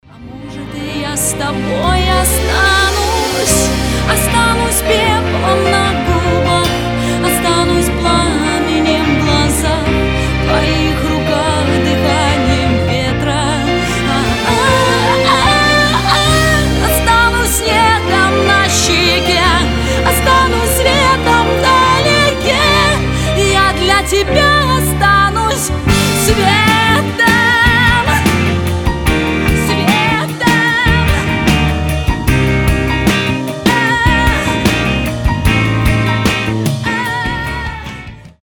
романтические , поп
нежные , русские , до мурашек